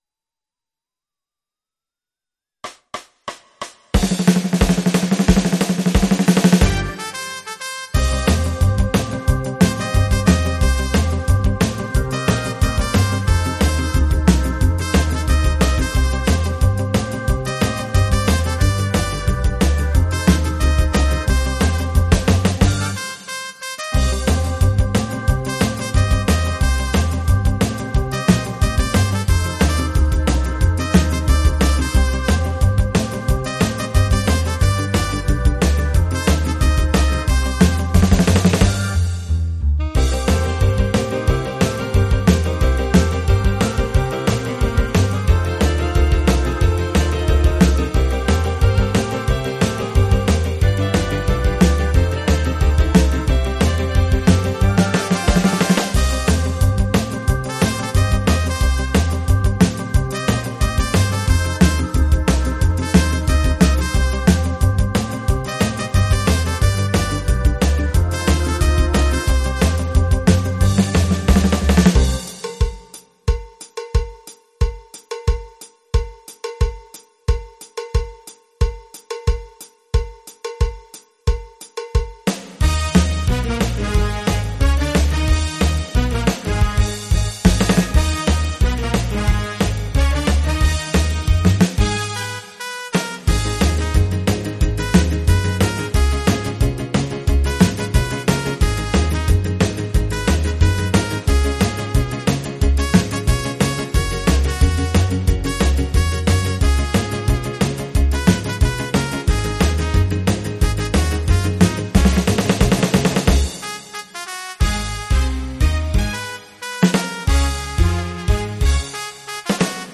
Jazz Trio (PRO MIDI Karaoke INSTRUMENTAL VERSION